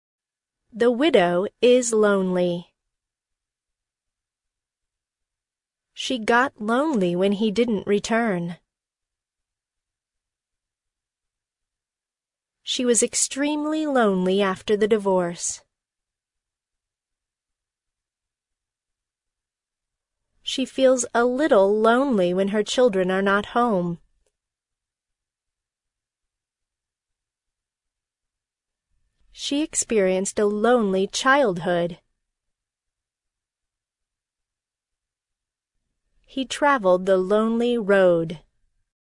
lonely-pause.mp3